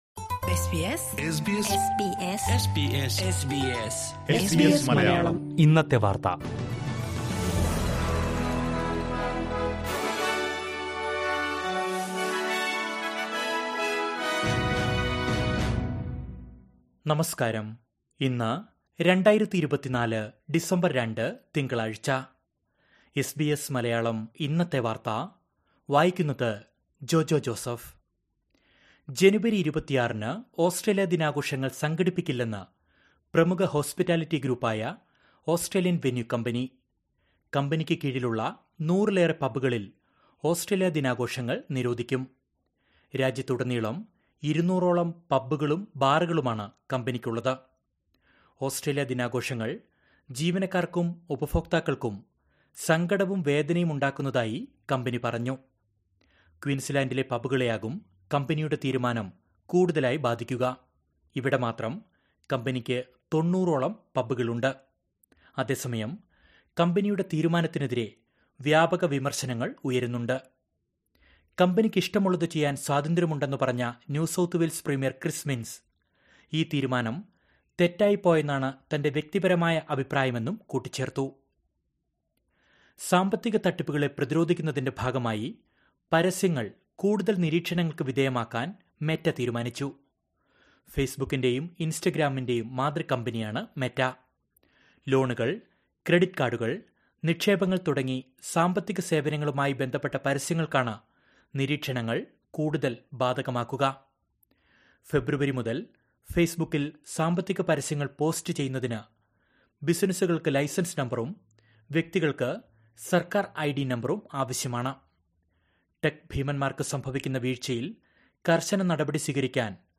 2024 ഡിസംബർ ഒന്നിലെ ഓസ്ട്രേലിയയിലെ ഏറ്റവും പ്രധാന വാർത്തകൾ കേൾക്കാം...